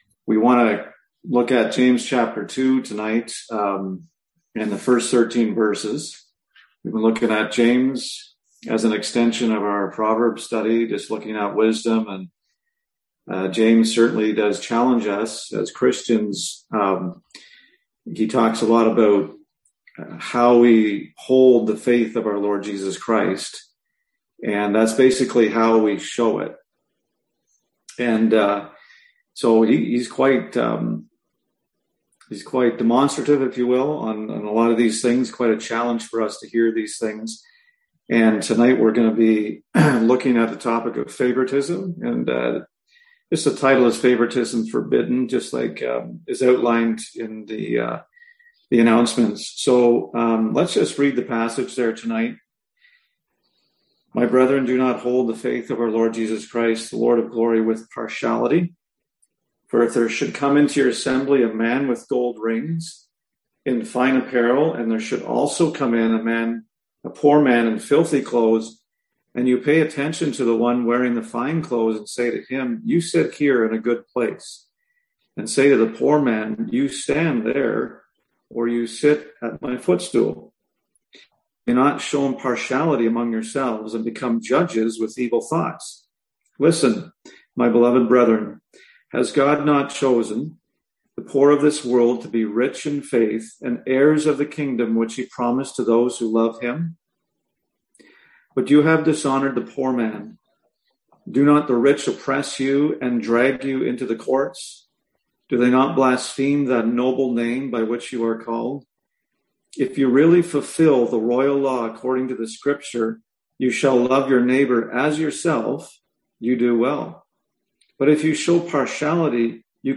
James 2021 Passage: James 2:1-13 Service Type: Seminar Topics